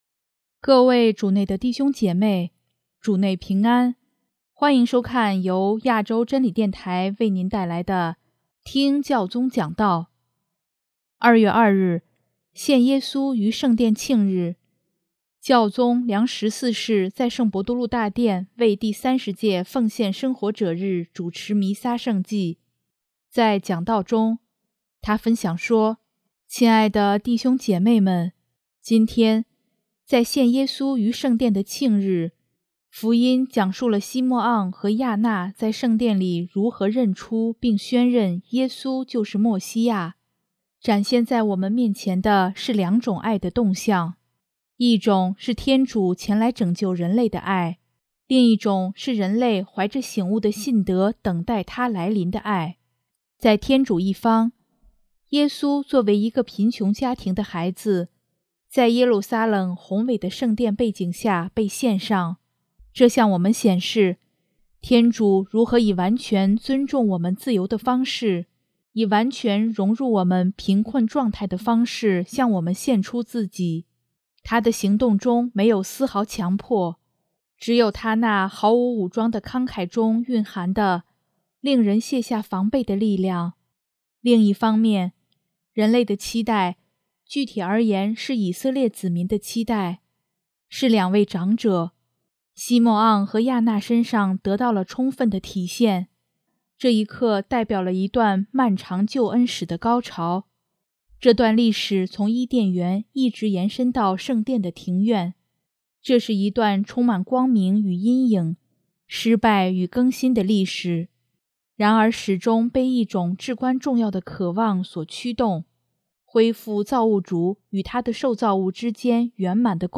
2月2日，献耶稣于圣殿庆日，教宗良十四世在圣伯多禄大殿为第30届奉献生活者日主持弥撒圣祭，在讲道中，他分享说：